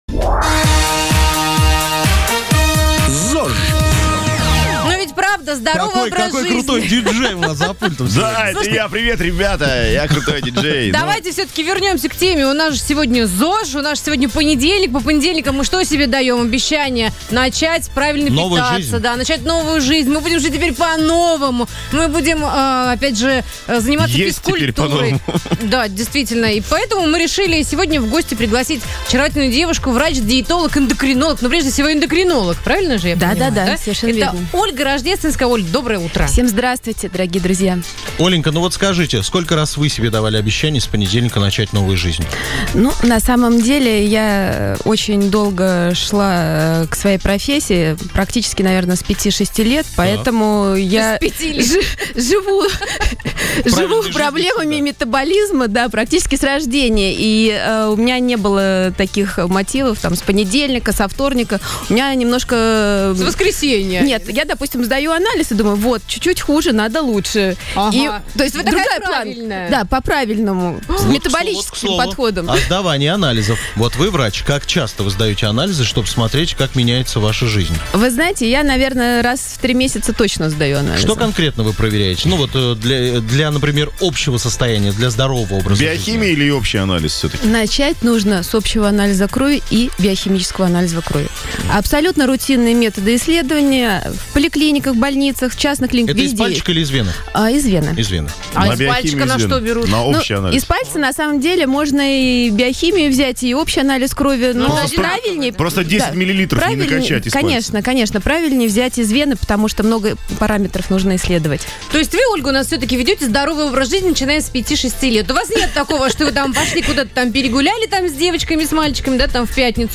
Источник: Радио Шансон